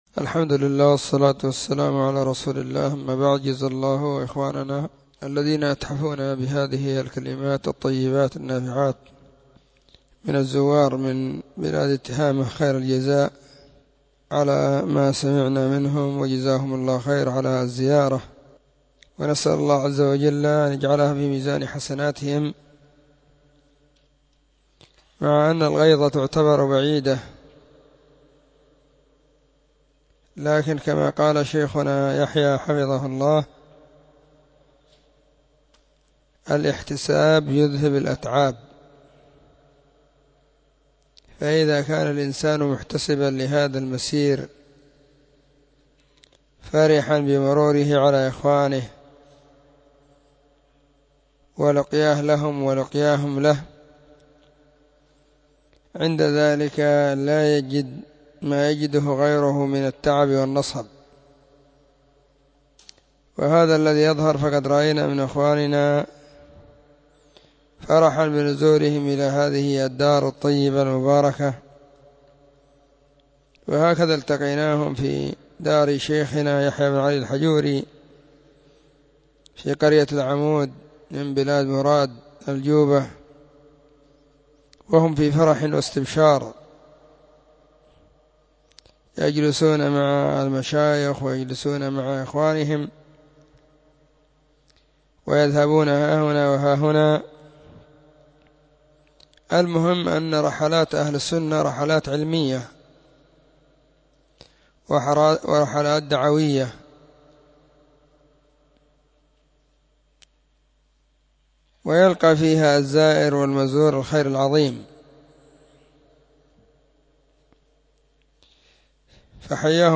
🎙كلمة بعنوان:شكر الشيخ حفظه الله للضيوف من تهامة